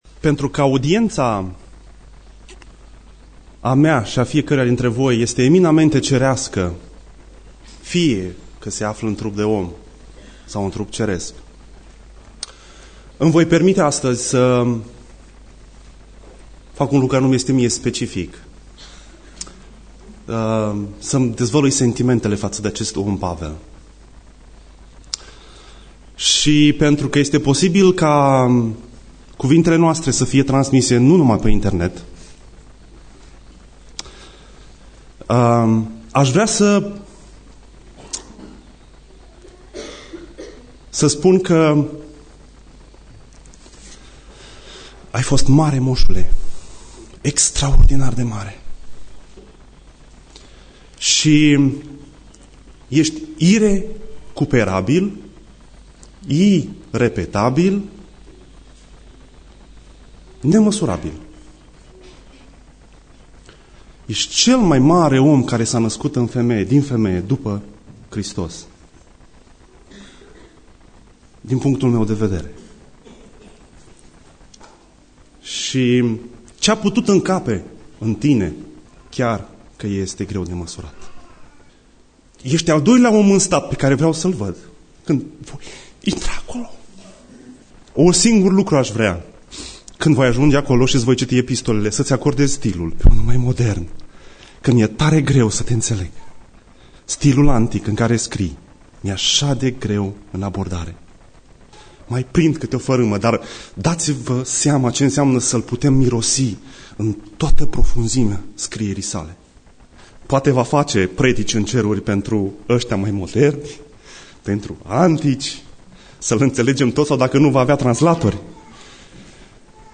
Predica Exegeza 2 Timotei 1-1.8